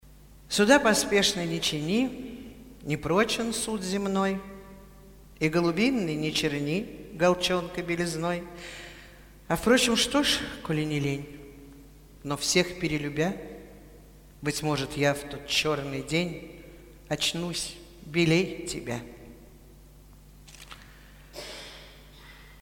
1. «Цветаева Марина – Суда поспешно не чини… (читает Светлана Крючкова)» /
tsvetaeva-marina-suda-pospeshno-ne-chini-chitaet-svetlana-kryuchkova